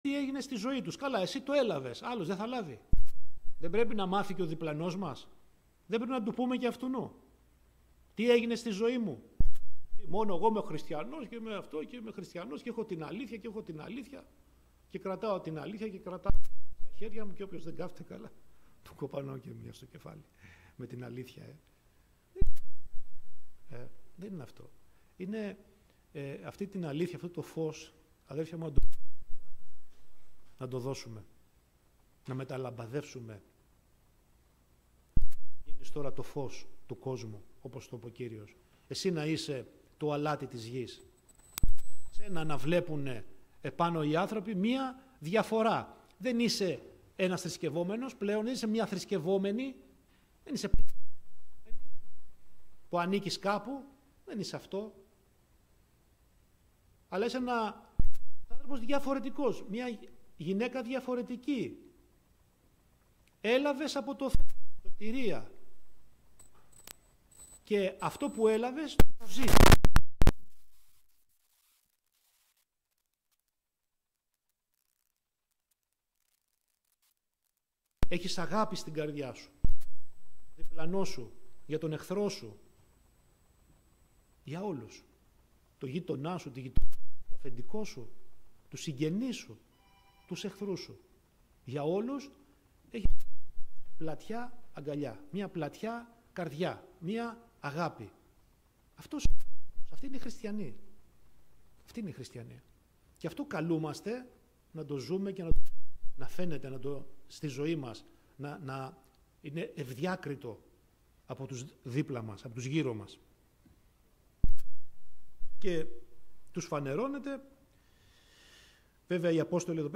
Κήρυγμα Ευαγγελίου